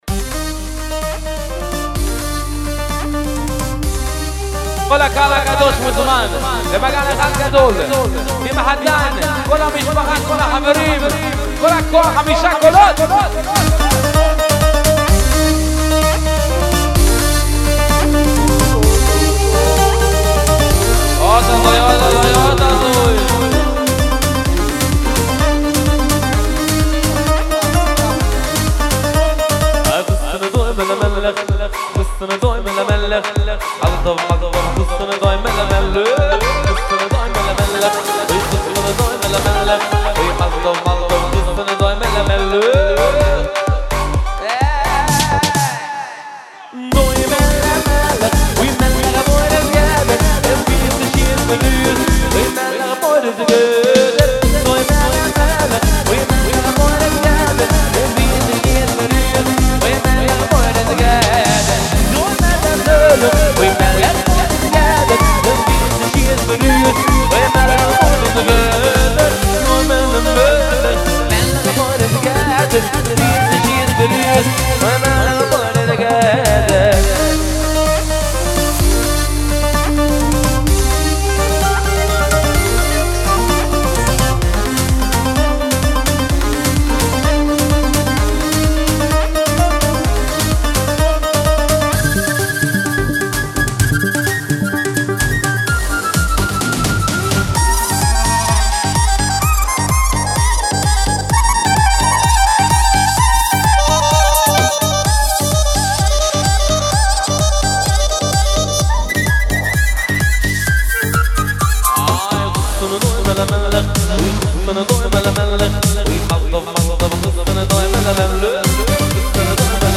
תשמעו איזה פתיח מזרחי מטורף הוא מכניס שם